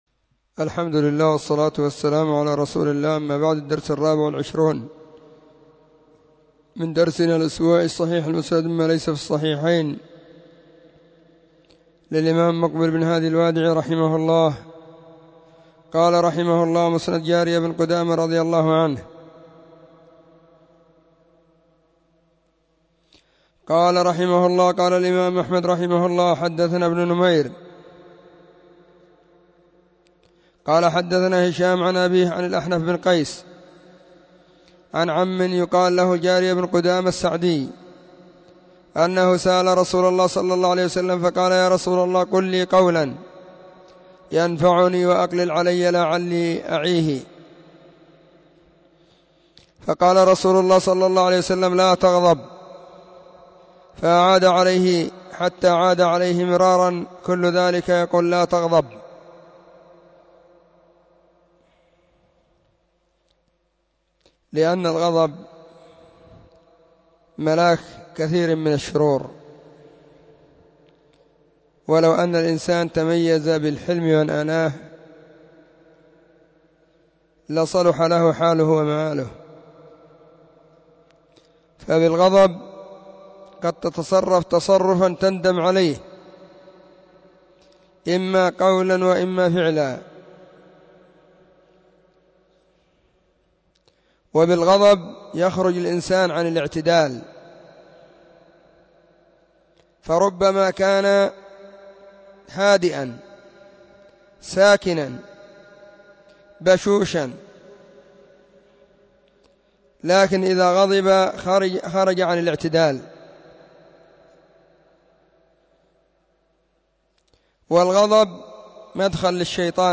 📖 الصحيح المسند مما ليس في الصحيحين,الدرس: 24